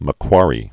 (mə-kwärē, -kwôrē)